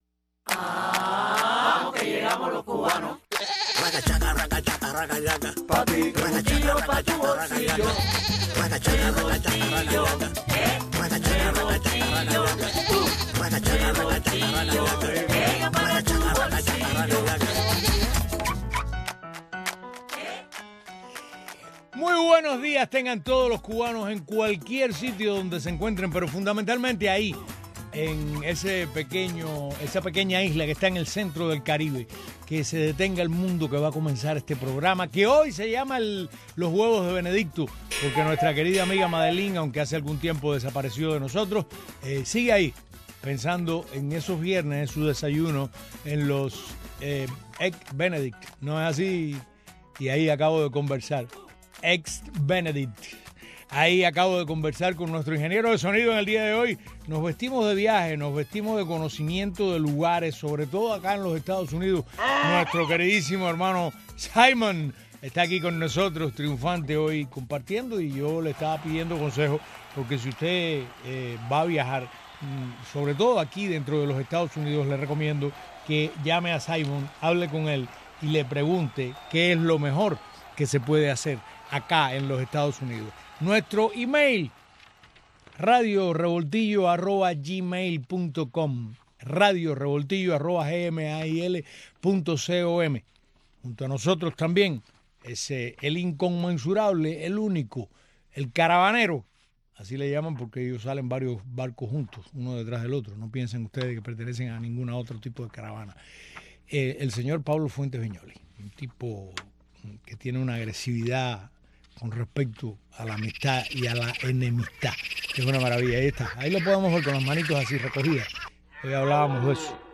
Hoy programa abierto para responder sus correos, sus llamadas y su contacto en las redes sociales.